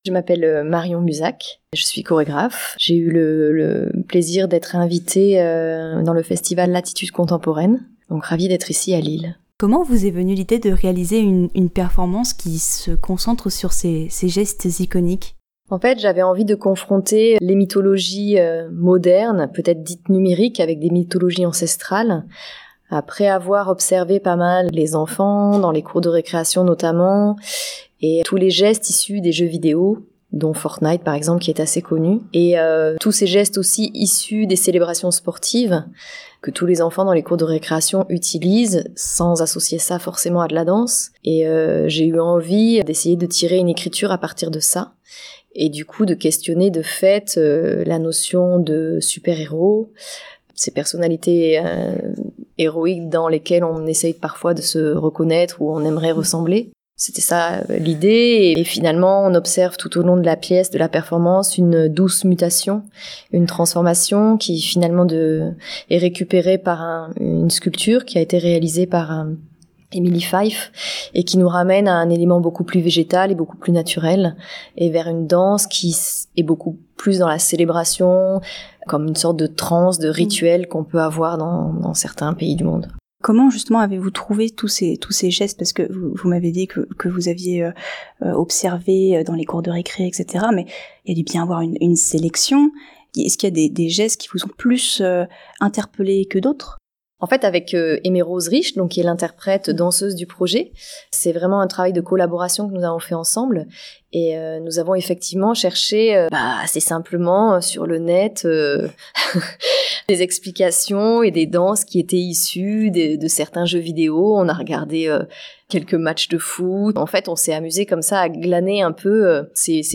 �couter la version longue (interview) .